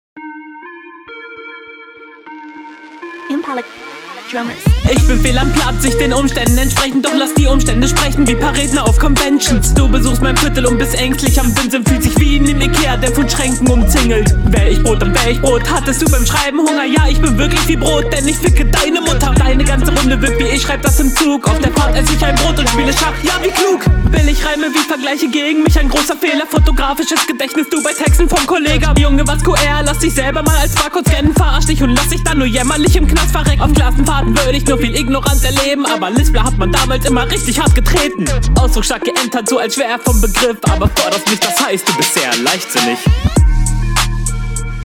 Flow: Das klingt alles nicht sehr rund und wirkt teils sehr unroutiniert, aber ist wenn …
Ein Stereoerlebnis ist leider nicht gegeben.
Flowlich ist das hier schwächer als die HR1, das wirkt alles etwas angestrengt gerappt, du …